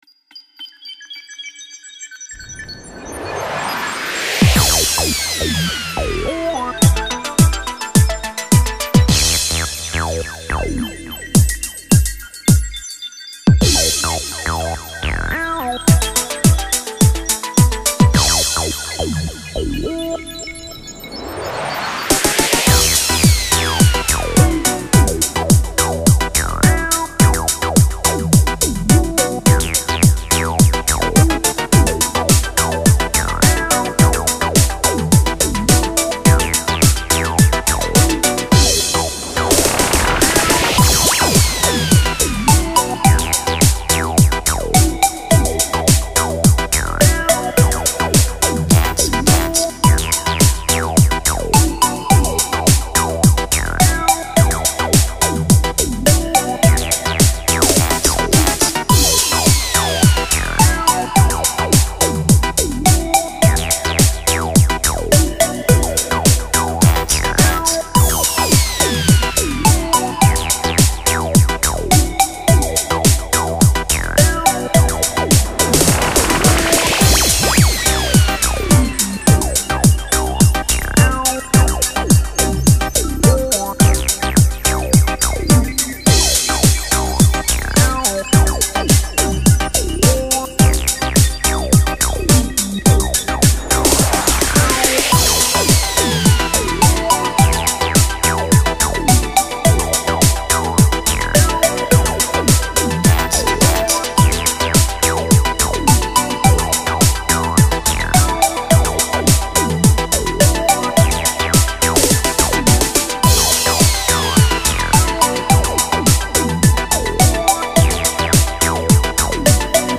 Genre: New Age, Electronic, Chillout, Ambient